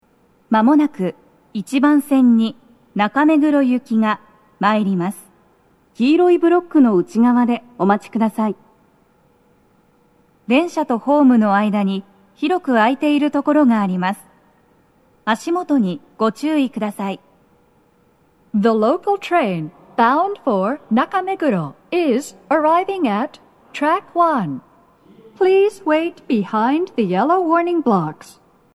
スピーカー種類 BOSE天井型
🎵接近放送
鳴動は、やや遅めです。
１番線 中目黒方面 接近放送 【女声